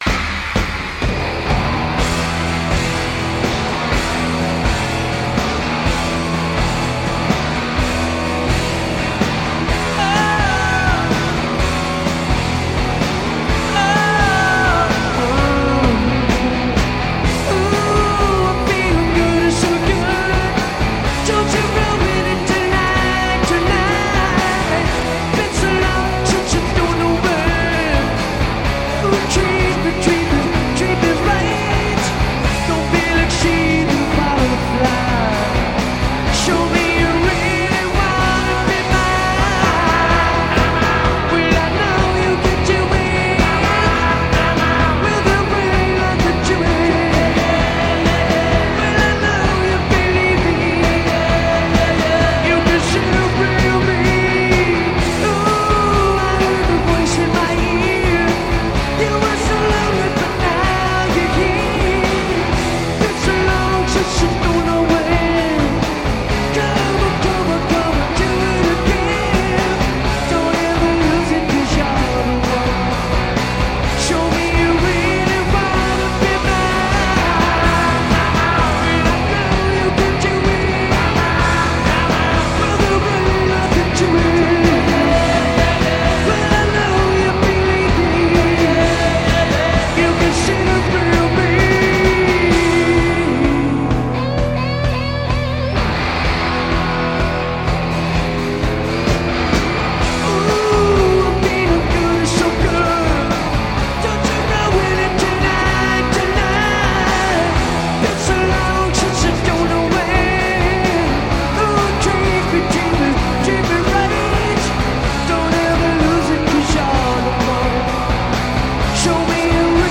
Live at Nippon Budokan, Tokyo, JPN